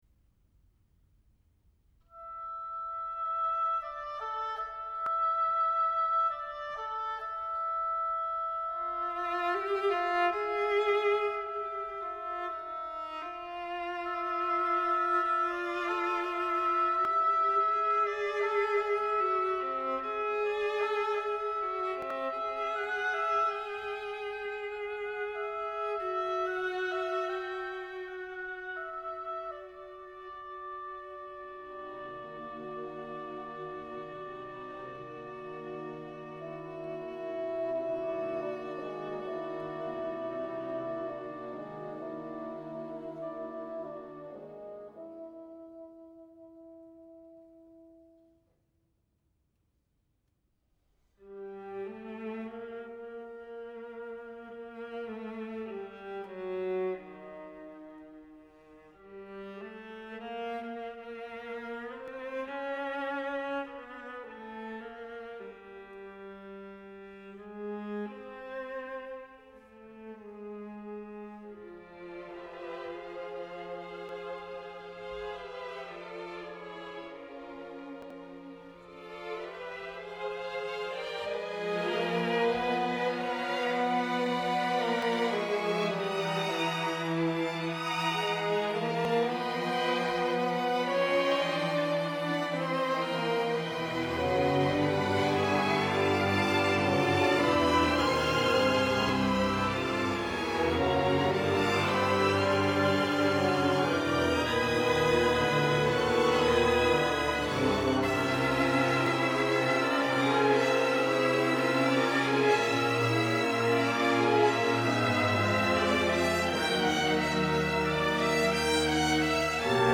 a suite for viola, orchestra, and wordless chorus.